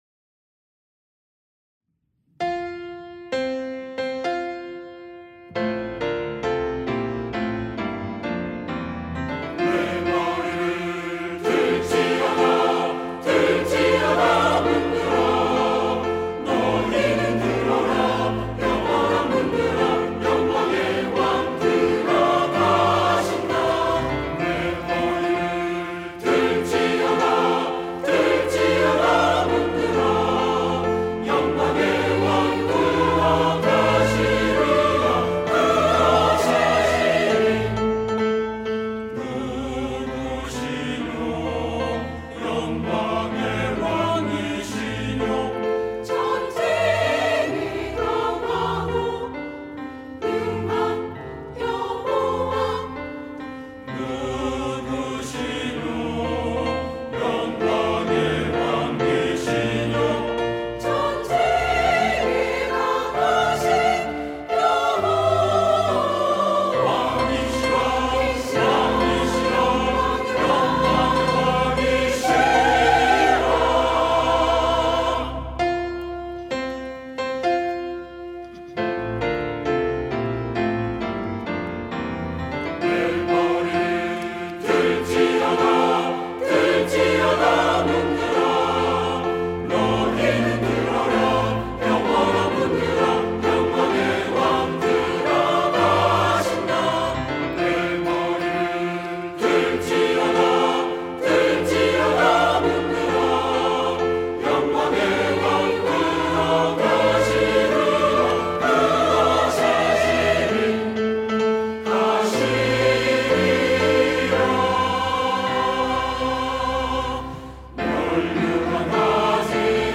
시온(주일1부) - 영광의 왕
찬양대